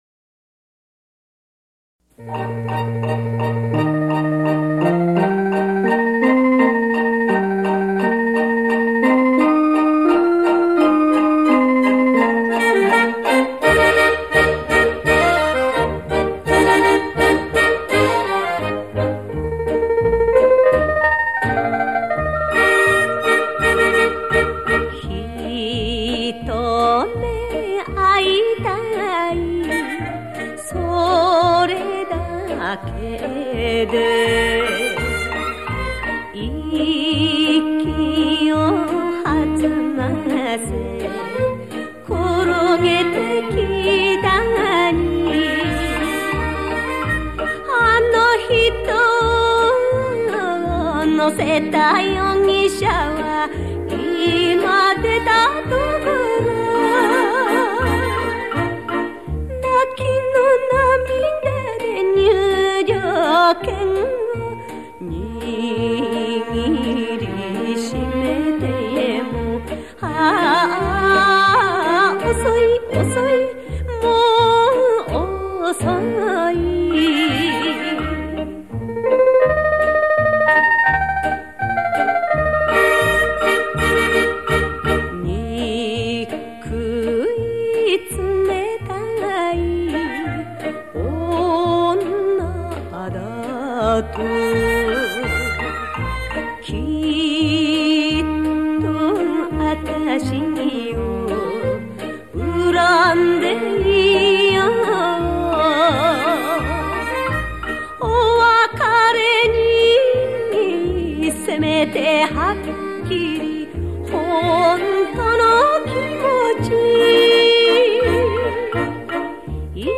怀念演歌 旋律再次浮现.....
收录日本演歌精选/曲曲动听 朗朗上口